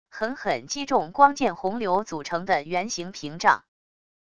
狠狠击中光剑洪流组成的圆形屏障wav音频